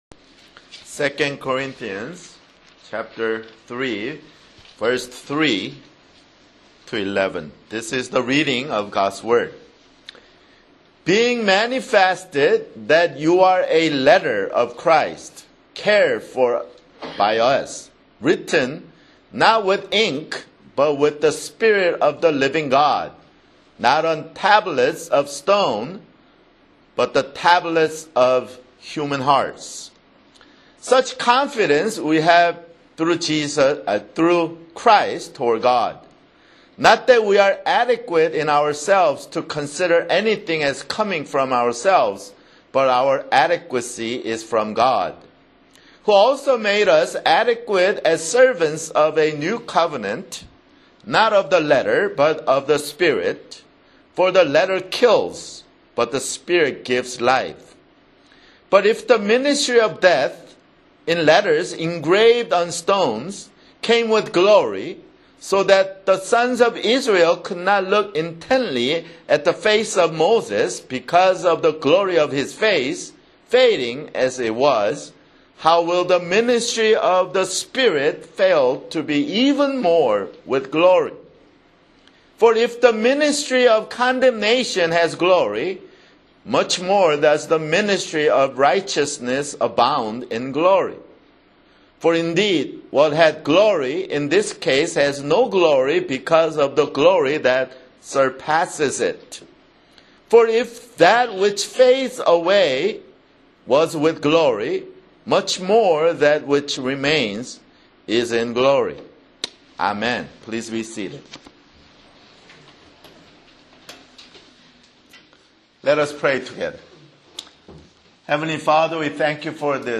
[Sermon] 2 Corinthians (15)